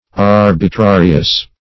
Search Result for " arbitrarious" : The Collaborative International Dictionary of English v.0.48: Arbitrarious \Ar`bi*tra"ri*ous\, a. [L. arbitrarius.
arbitrarious.mp3